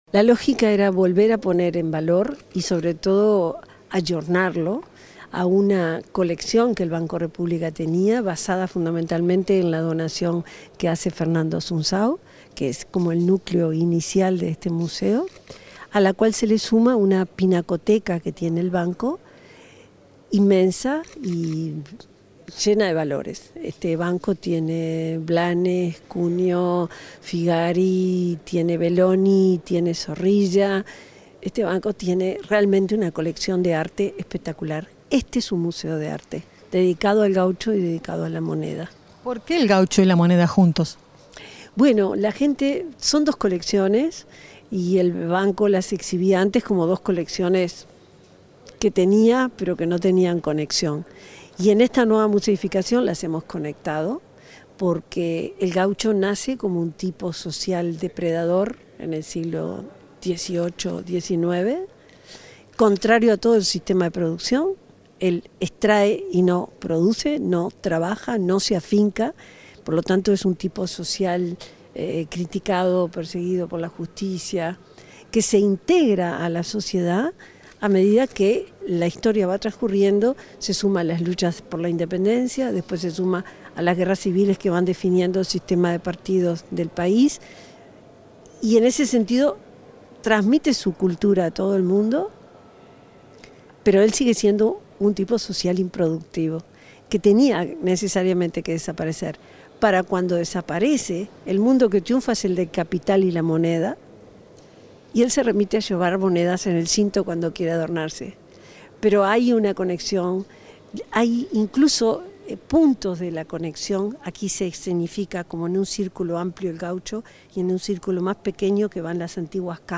Entrevista a la subsecretaria de Educación y Cultura, Ana Ribeiro